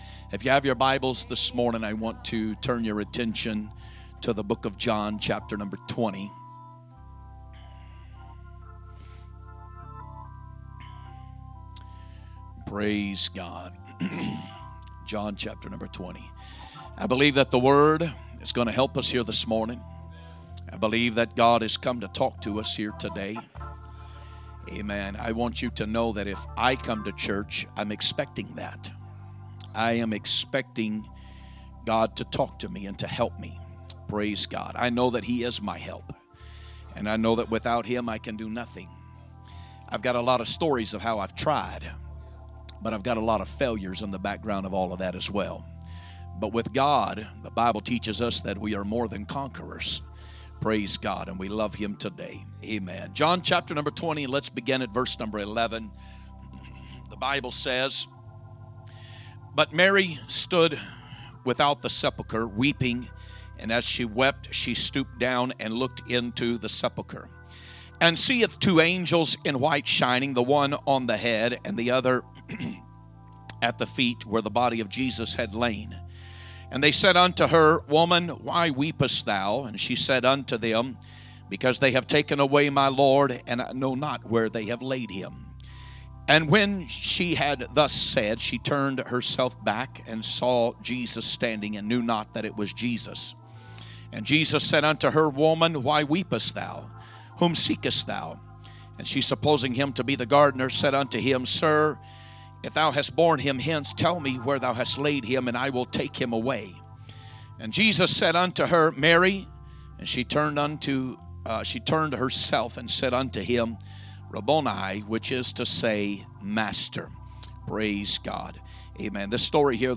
Easter Sunday Morning Message
2025 Sermons